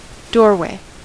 Ääntäminen
Ääntäminen US Haettu sana löytyi näillä lähdekielillä: englanti Käännös Ääninäyte Substantiivit 1.